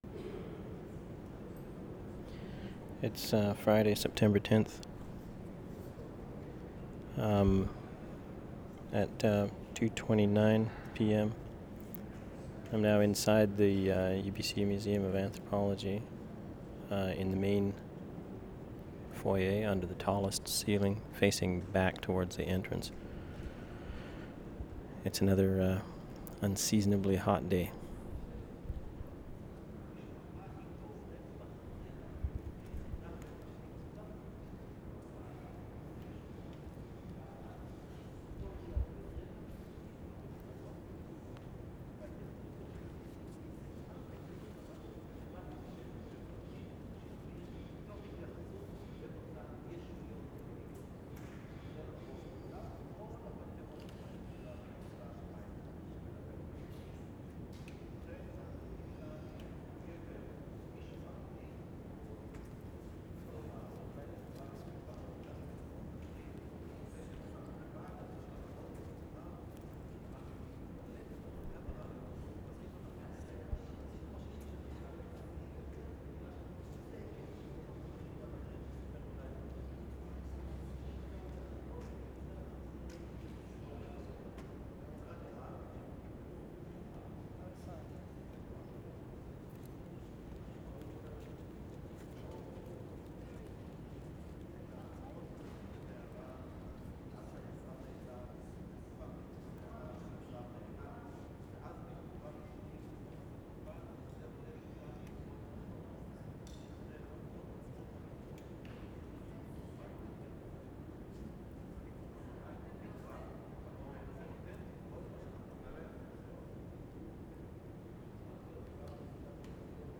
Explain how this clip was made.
WORLD SOUNDSCAPE PROJECT TAPE LIBRARY VANCOUVER, WEST SIDE , SEPT 9-10, 1993 UBC Museum of Anthropology, interior 8:41